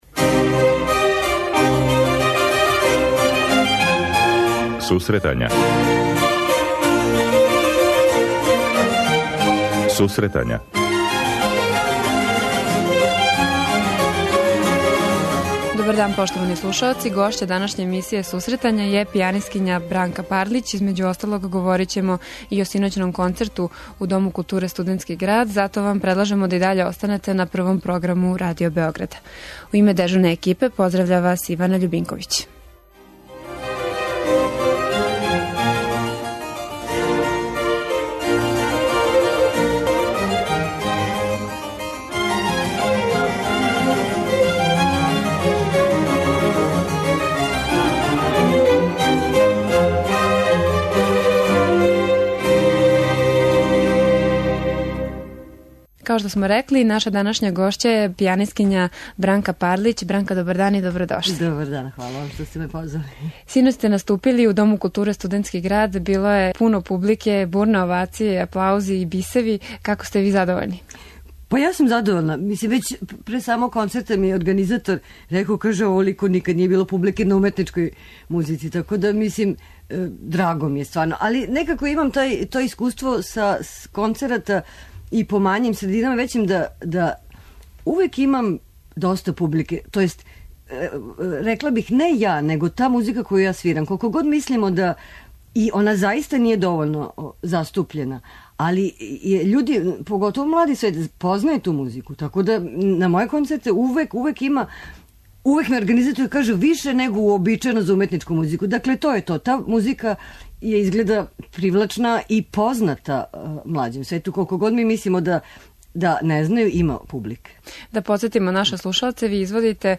преузми : 26.38 MB Сусретања Autor: Музичка редакција Емисија за оне који воле уметничку музику.